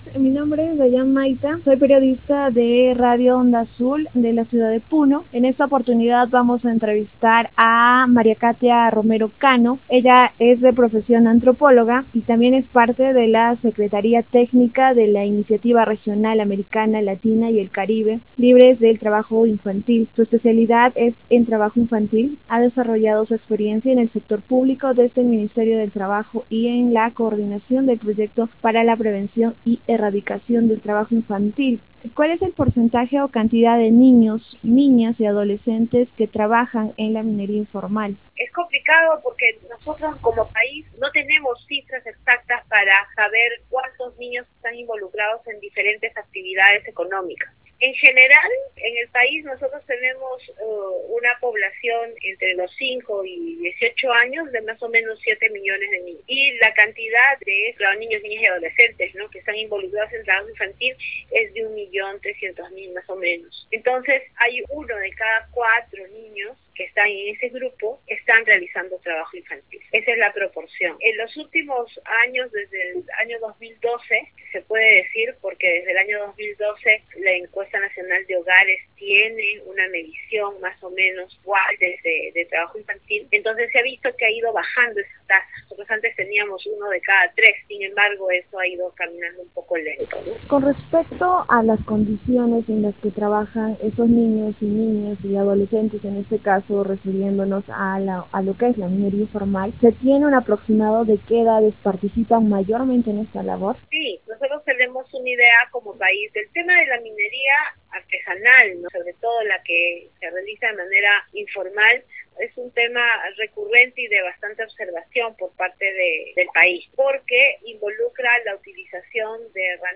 [ENTREVISTA] La minería informal un trabajo peligroso en el que están inmersos niños y adolescentes - Televisión Tarapoto